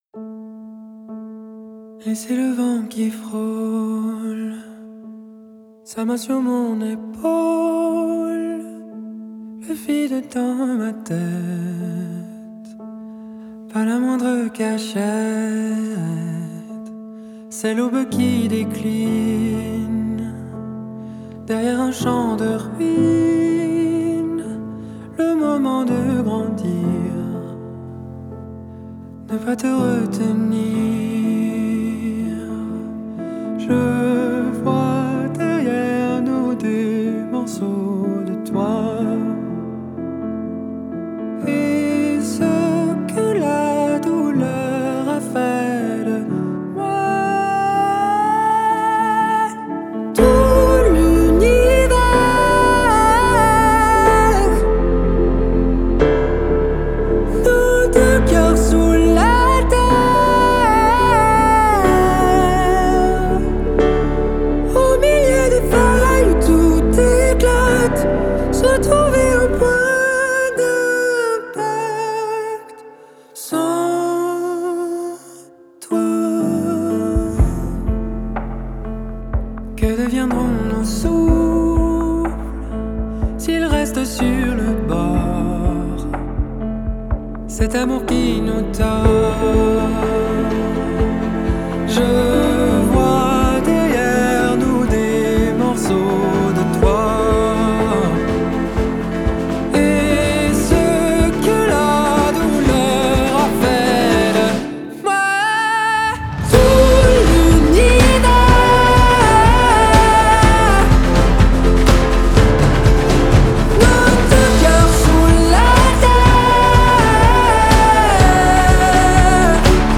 эмоциональный балладный трек в жанре поп с элементами соула